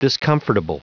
Prononciation du mot discomfortable en anglais (fichier audio)